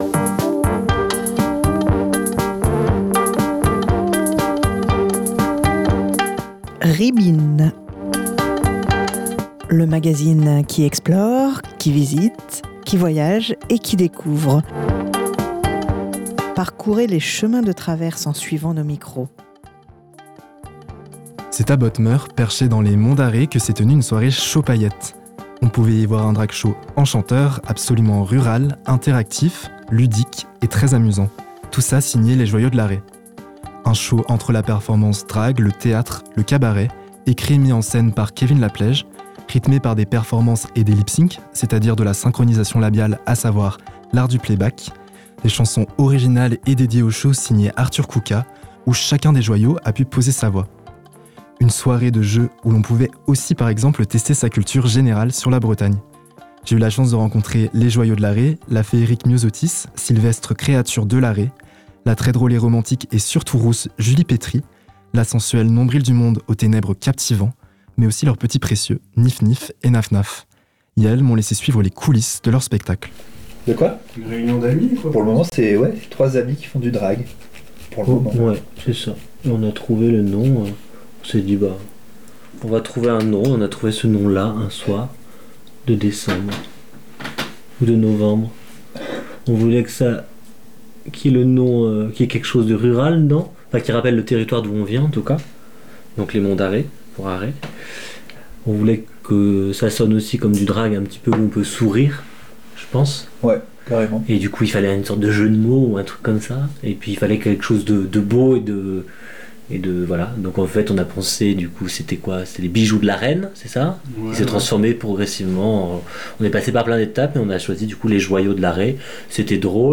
J'ai eu la chance de suivre quelques répétitions des Joyaux de l'Arrée et de poser mon micro alors qu'iels se maquillaient à quelques heures du spectacle...